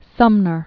(sŭmnər), Charles 1811-1874.